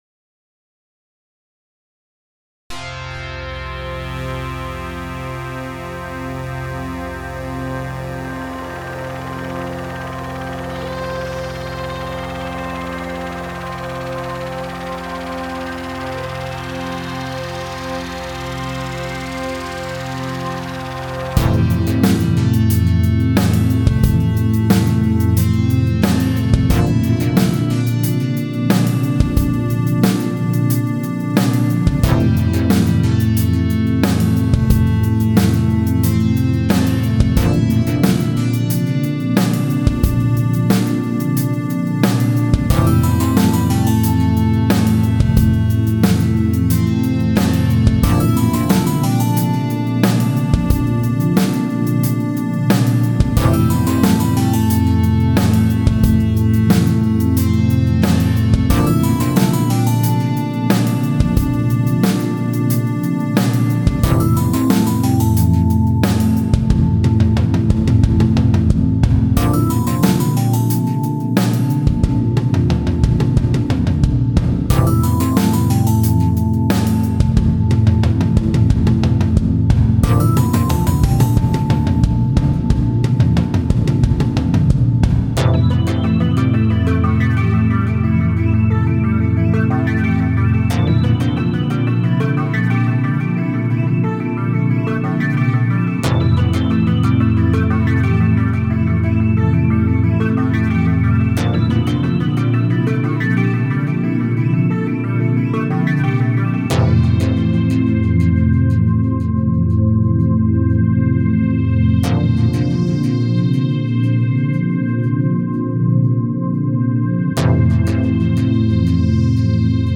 where I will share songs created at home with lots of love.